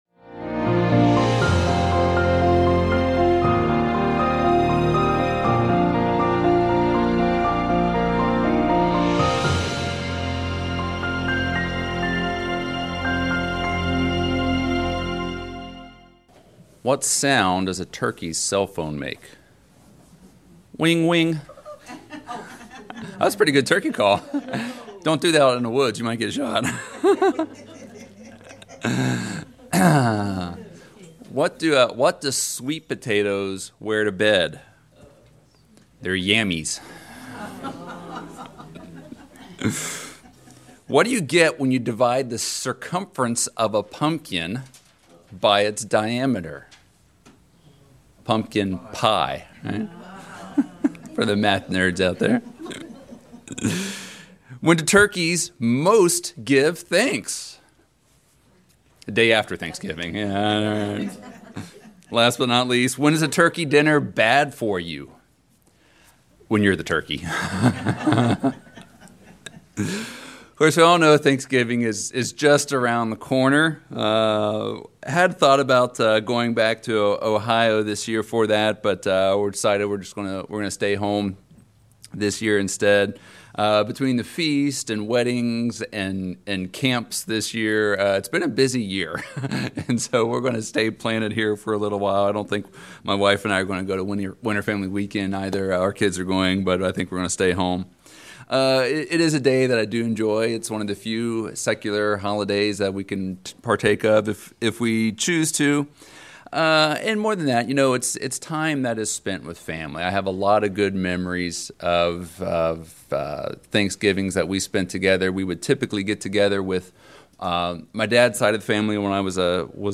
Sermons
Given in Charlotte, NC Hickory, NC